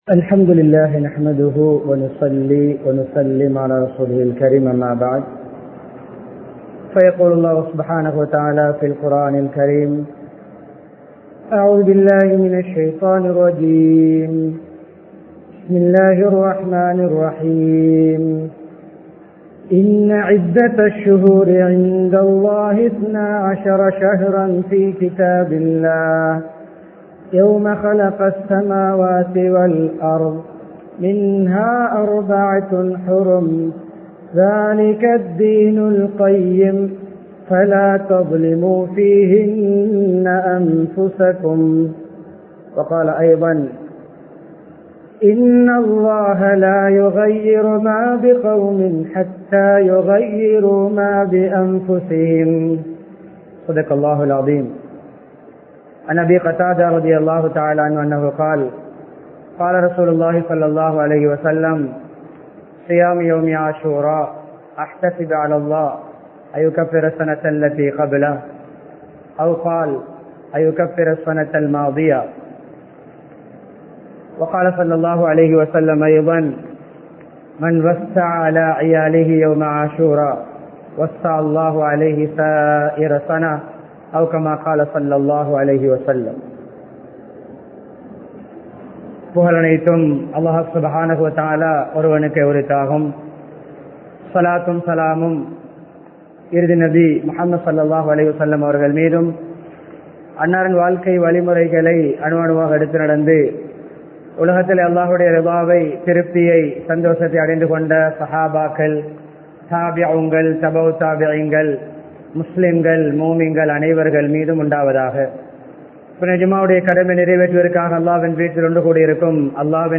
முஹர்ரம் மாதத்தின் வரலாறு | Audio Bayans | All Ceylon Muslim Youth Community | Addalaichenai
Jumua Masjidh